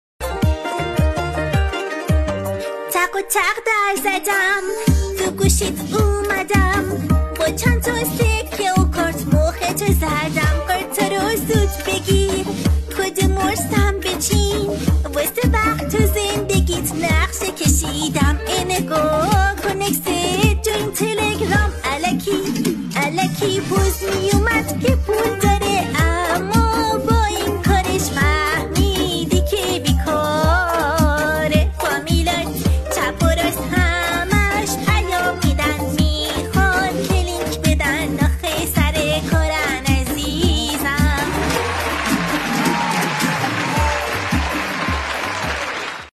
اهنگ رپ